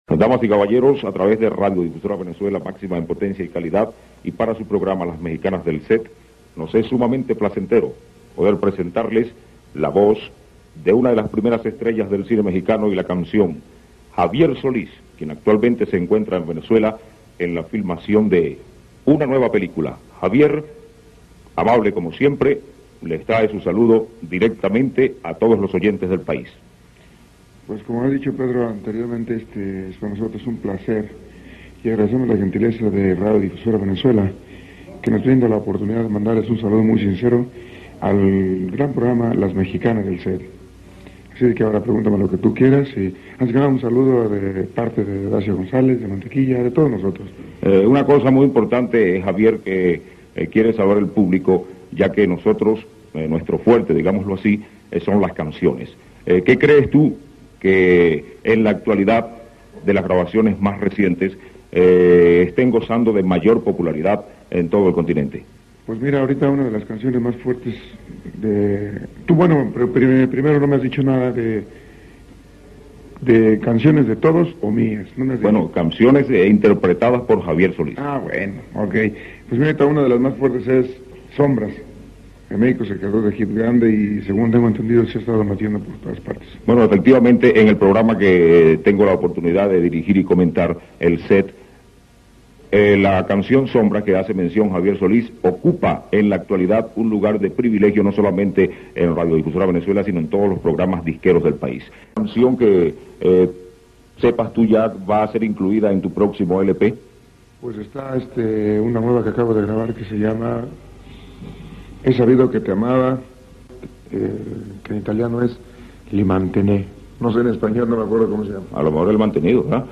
Una entrevista y una canción
entrevista-a-javier-solc3ads-en-venezuela.mp3